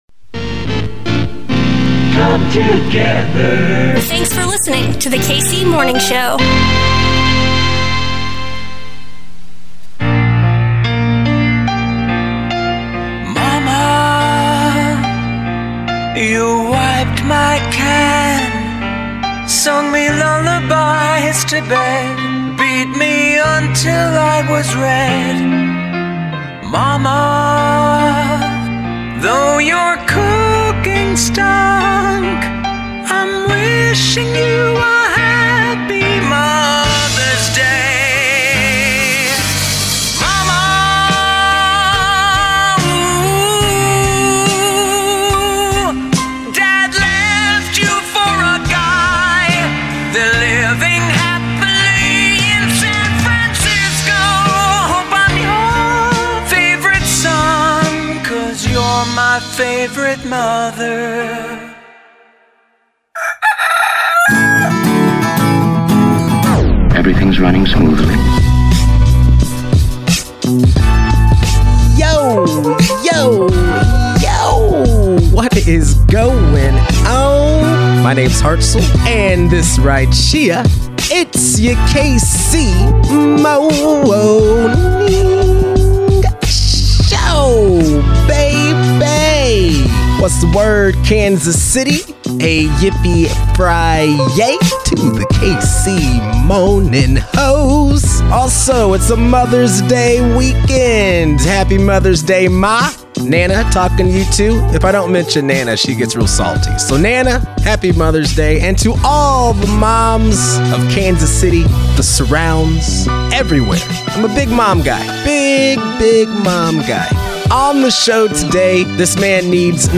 LIVE from The Rino!